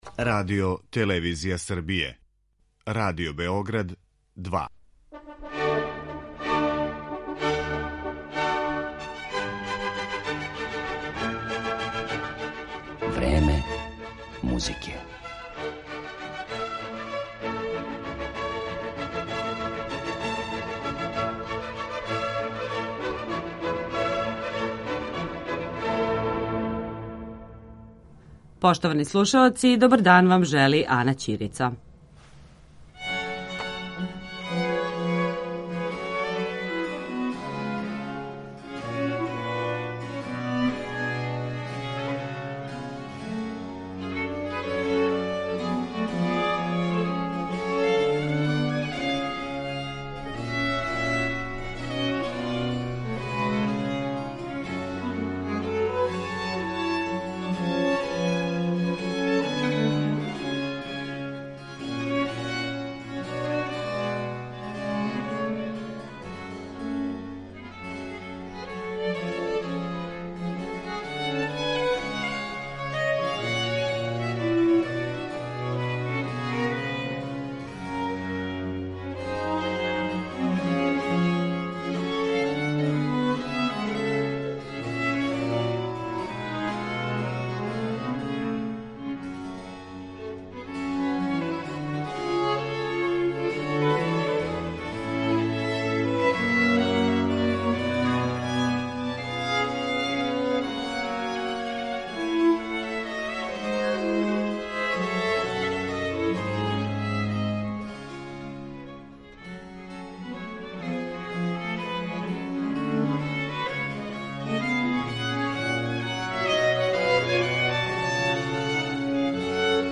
Виолинисткиња